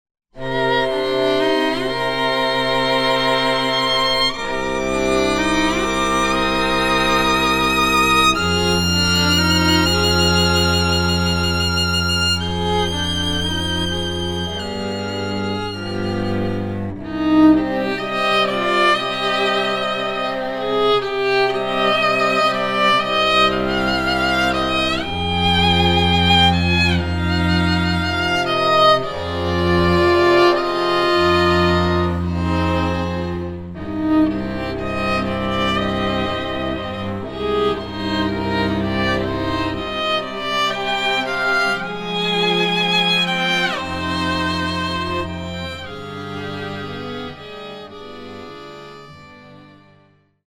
Recorded in June 2019 in Los Angeles